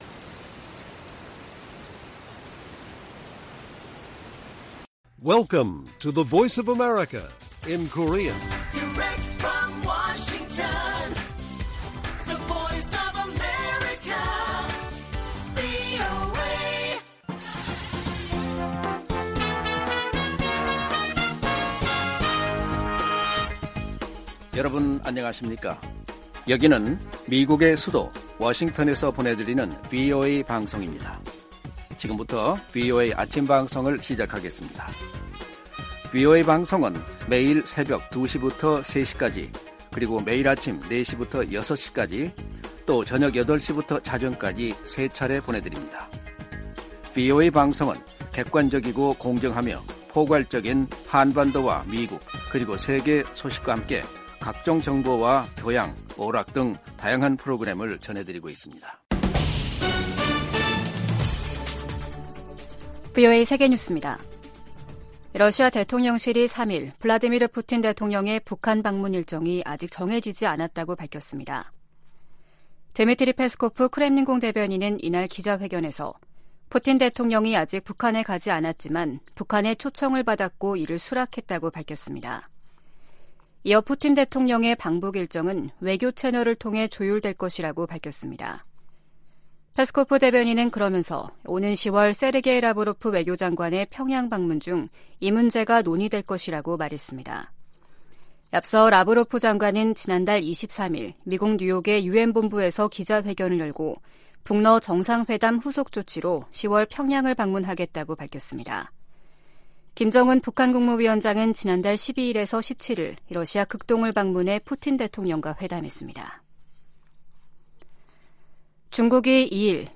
세계 뉴스와 함께 미국의 모든 것을 소개하는 '생방송 여기는 워싱턴입니다', 2023년 10월 4일 아침 방송입니다. '지구촌 오늘'에서는 유럽연합(EU) 외교장관들이 우크라이나에 지속적 지원을 다짐한 소식 전해드리고, '아메리카 나우'에서는 공화당 강경파가 정부 '셧다운'을 피하기 위해 단기 임시 예산안 통과를 주도한 같은 당 케빈 매카시 하원의장 해임 작업을 공식화한 이야기 살펴보겠습니다.